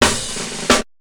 Break 3.wav